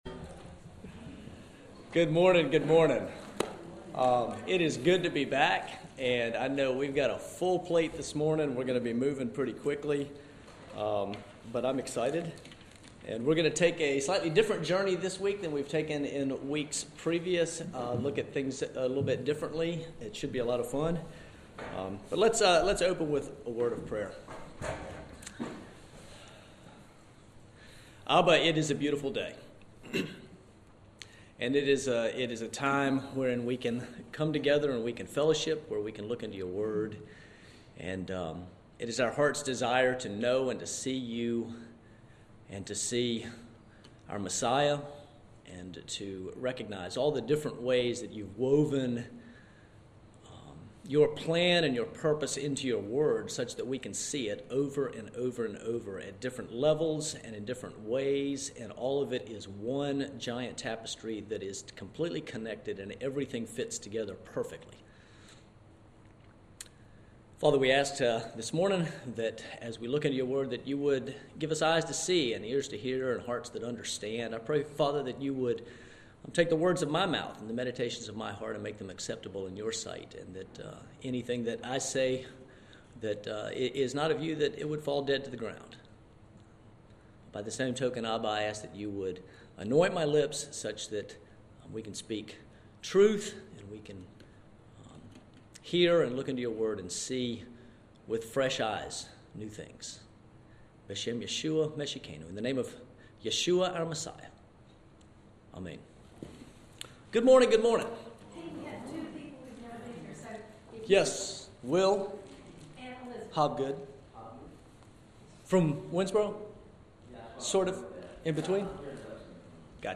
This week in Sunday School we took a look at Yeshua in the Tanakh as seen in David at the defeat of Goliath, I Samuel 17.
Here is the 45 minute audio of the lesson that ultimately connects David and Goliath with our Messiah’s Second Coming, also previously discussed on this blog.